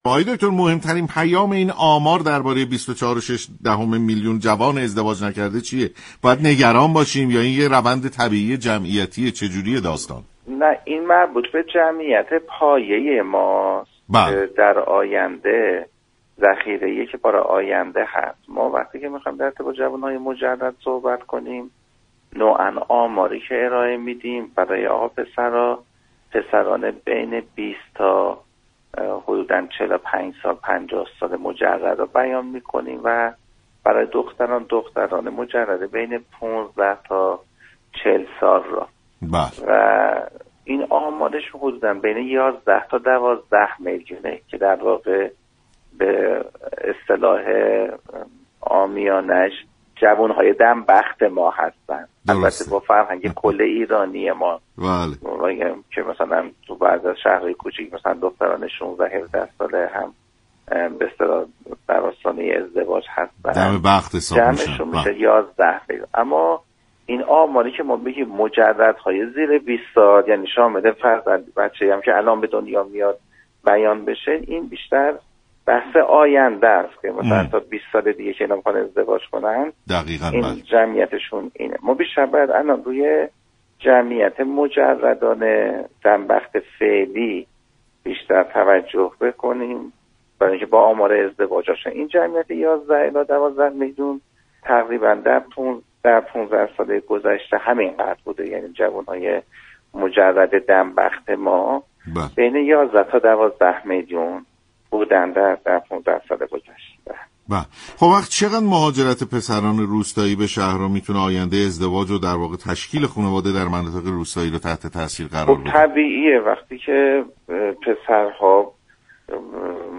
عضو كمیسیون اجتماعی مجلس در برنامه سلام صبح بخیر گفت: طی 15 سال اخیر میزان مجردین كشور همین مقدار و اندازه بوده ‌و دستگاه‌ها و نهادهای‌كشور بهتر است برای بهبود‌روند‌كار به‌این‌آمار‌ توجه‌كنند.